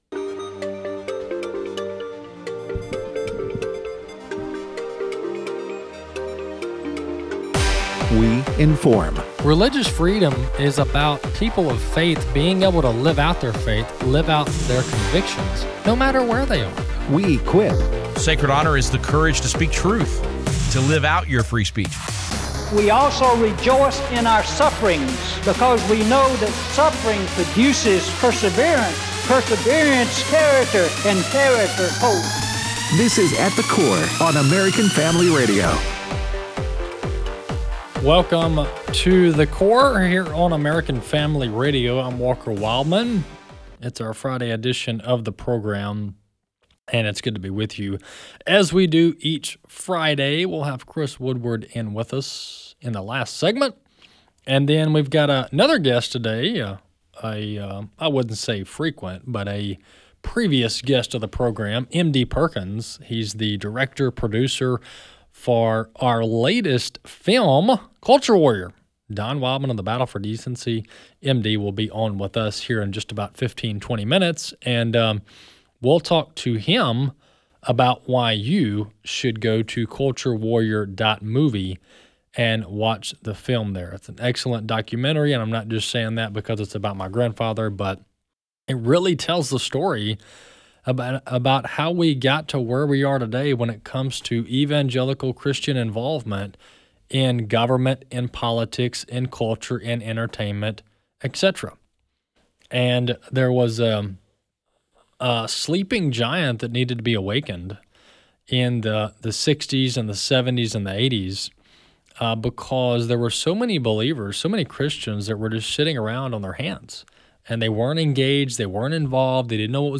joins us in studio to lay out what all went into making “Culture Warrior”